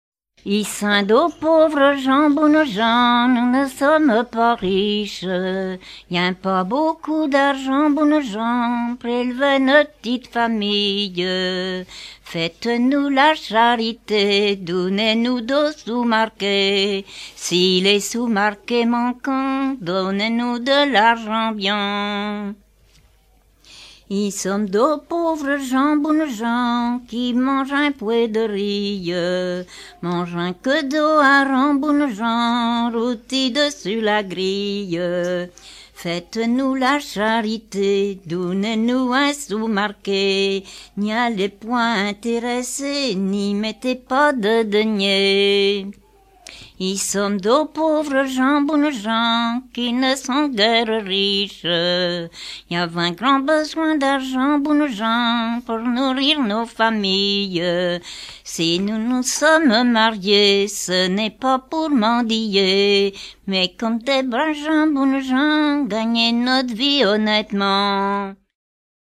circonstance : quête
Genre laisse